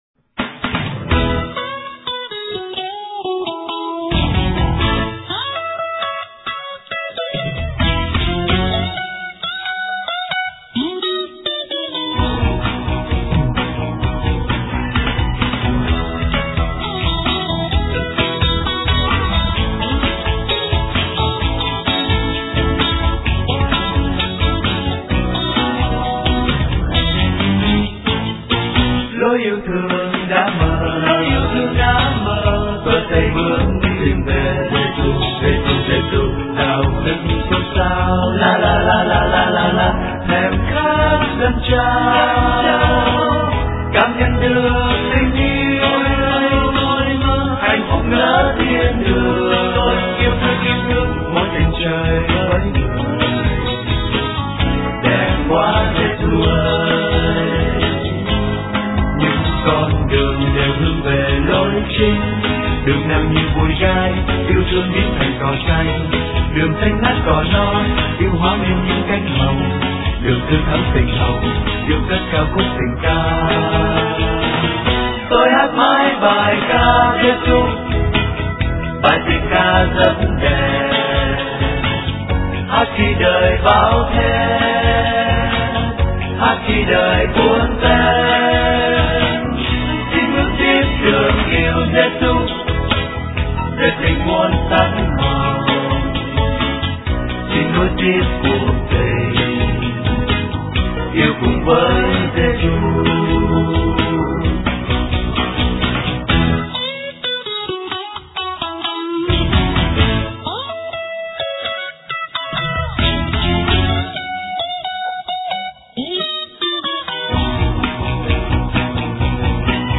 * Thể loại: Ngợi ca Thiên Chúa